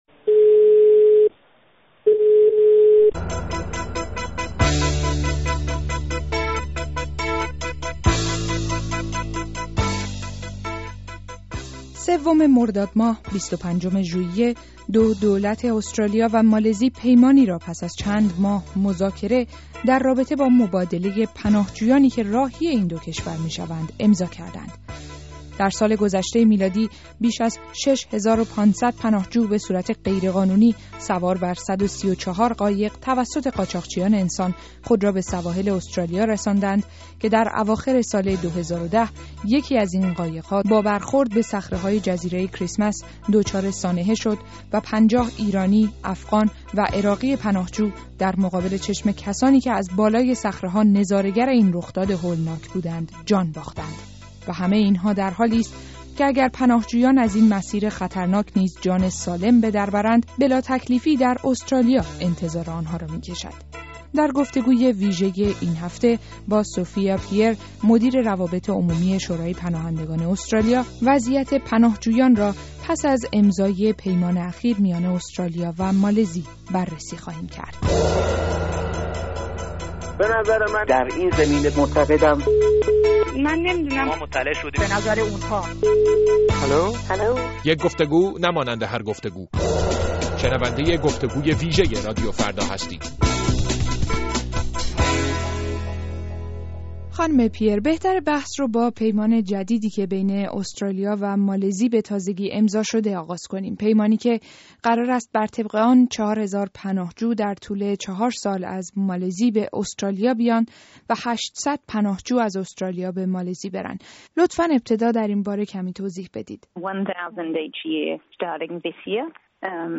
گفت‌وگوی ویژه این هفته در رادیوفردا اختصاص دارد به موضوع پناهجویانی که راهی مالزی می‌شوند و از آنجا مسیری پرخطر را به استرالیا می‌پیماند، تلاشی برای یافتن زندگی بهتر...